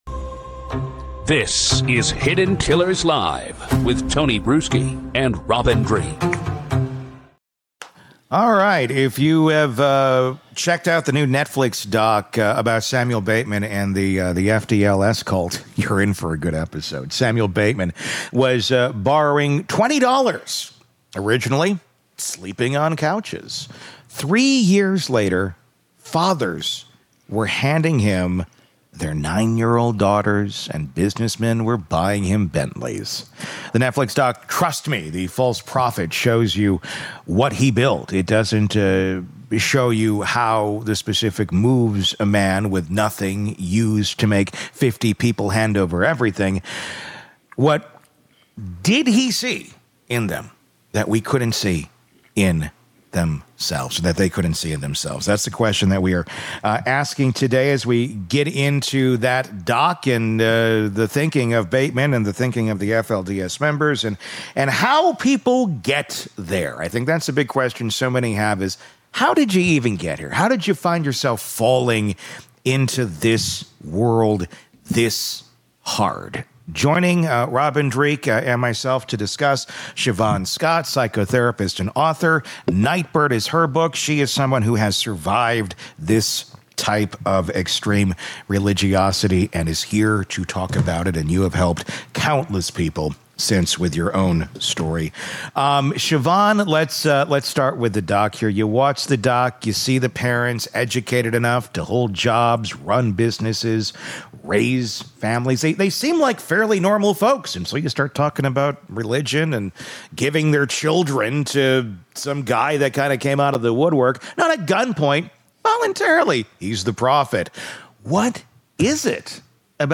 This conversation dissects the engineering.